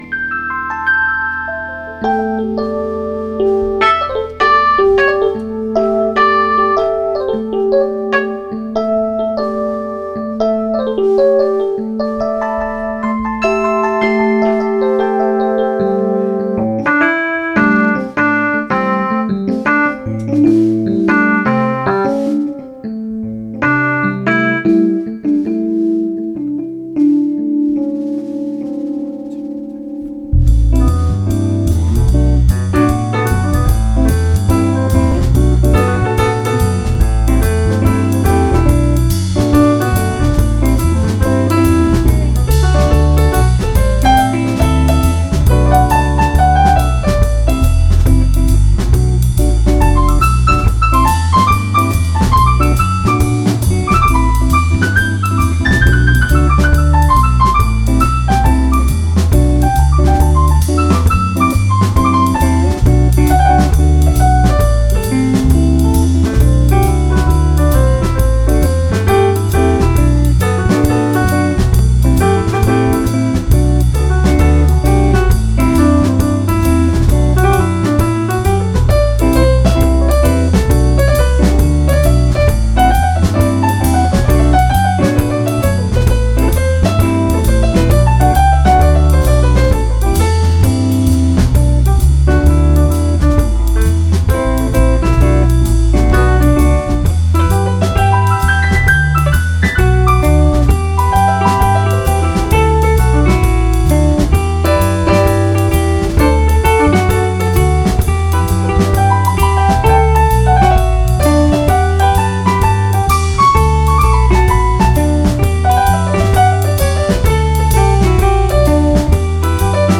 Guitar, Vocals
Guitar, Sax, Clarinet
Vocals, Trombone
Percussion, Guitar, Keys
Keyboards
Bass
Drums